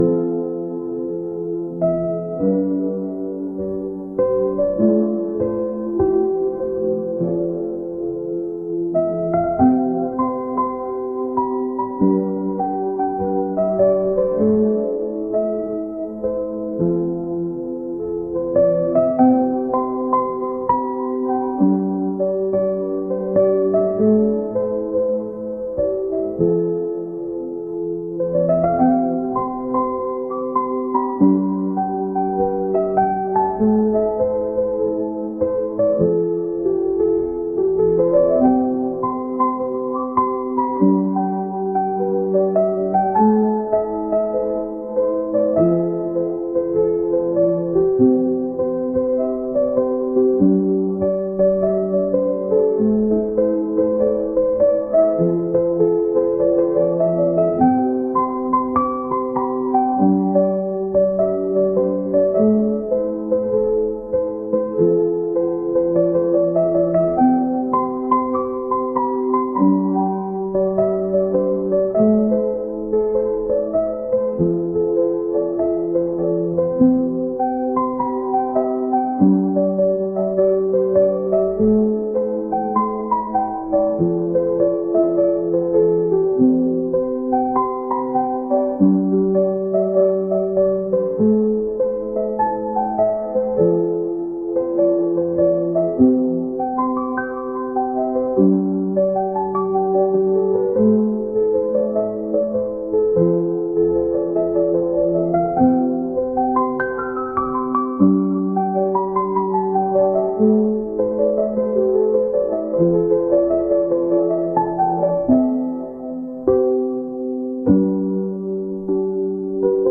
「癒し、リラックス」